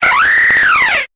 Cri de Brutalibré dans Pokémon Épée et Bouclier.